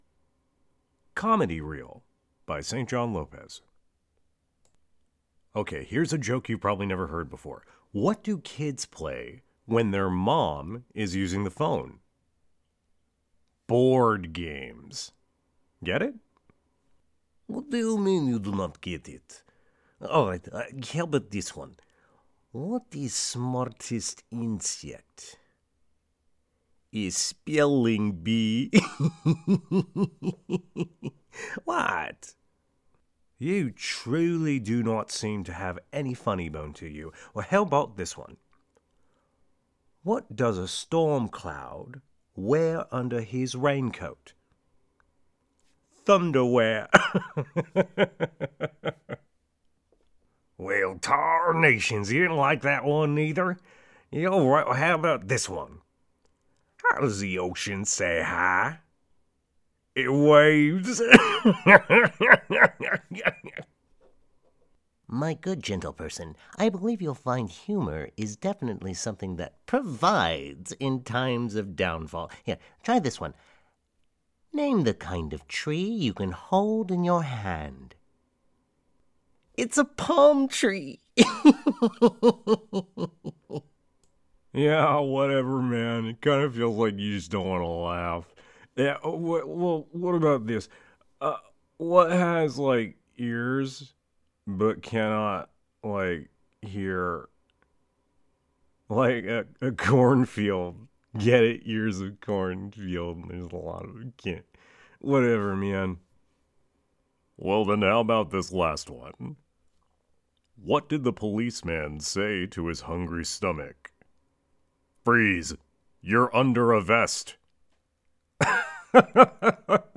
Comedy Reel (2024) - Humorous, Accented, Different, Varying Jokes.
Comedy Reel.mp3